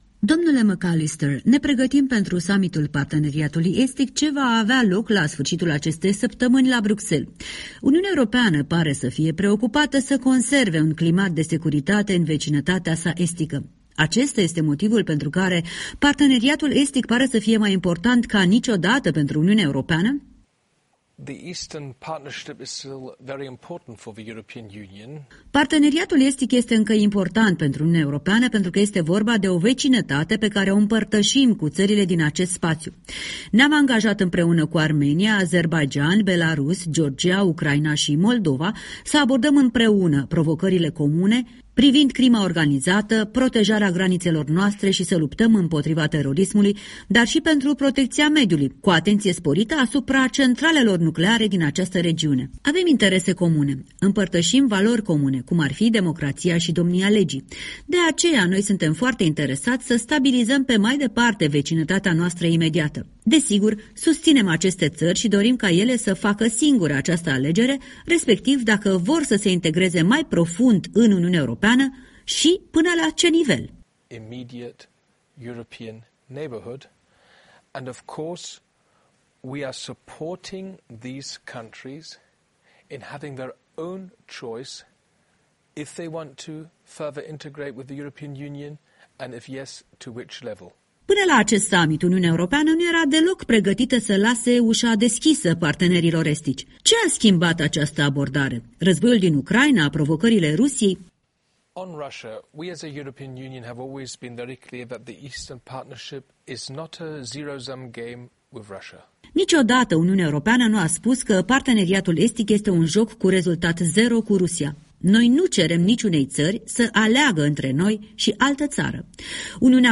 Un interviu cu președintele Comisiei pentru Afaceri Externe din Parlamentul European.